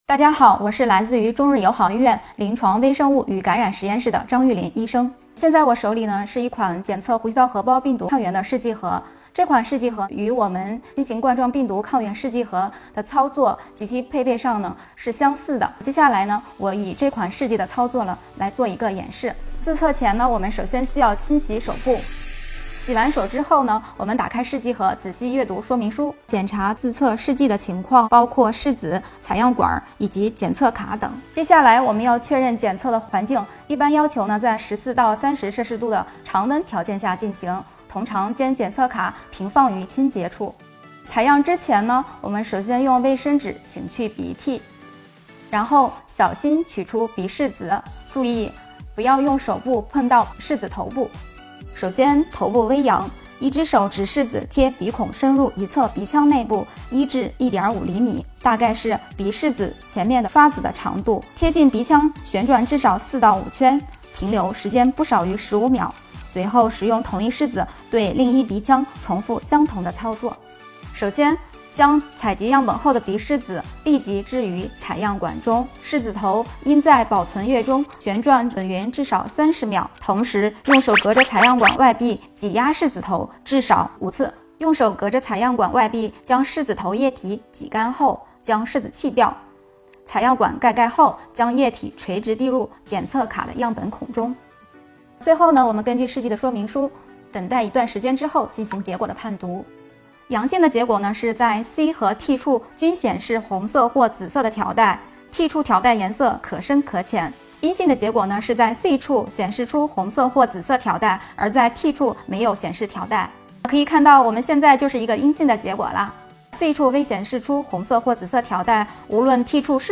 医务人员手把手来教你！